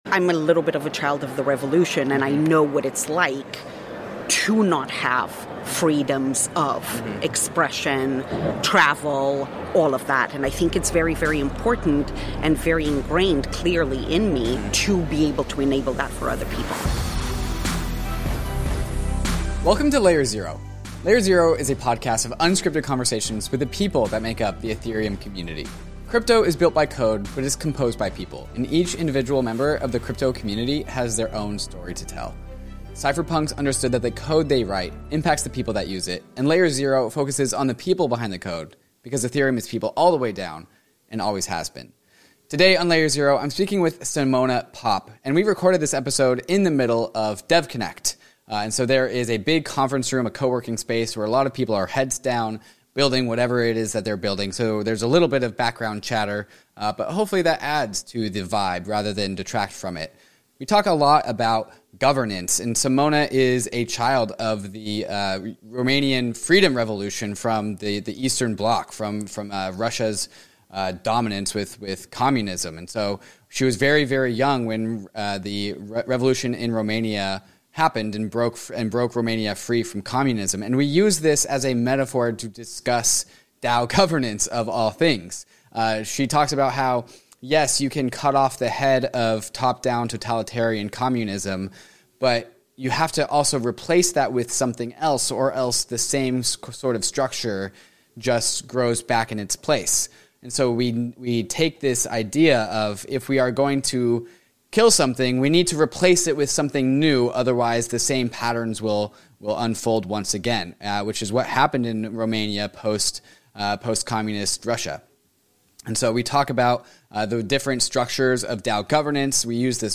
This episode takes place in the geographical heart of Layer Zero: the middle of DevConnect, the 2022 Amsterdam conference.